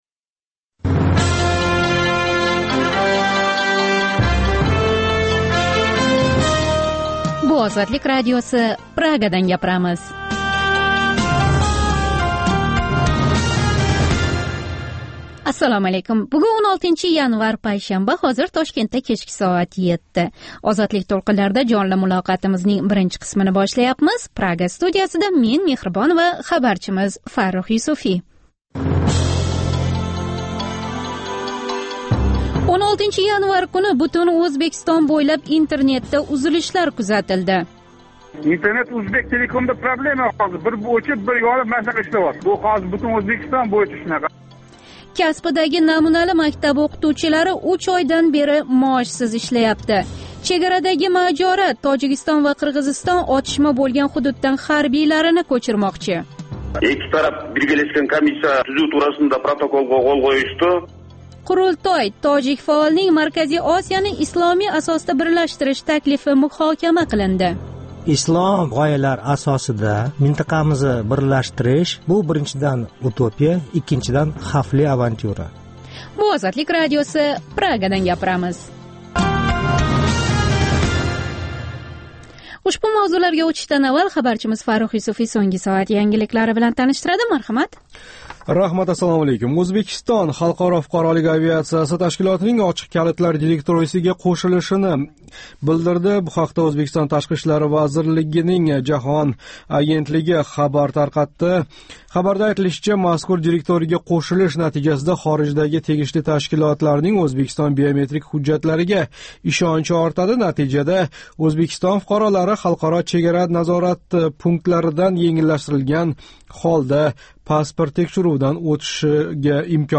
Жонли эфирдаги кечки дастуримизда сўнгги хабарлар, Ўзбекистон, Марказий Осиë ва халқаро майдонда кечаëтган долзарб жараëнларга доир тафсилот ва таҳлиллар билан таниша оласиз.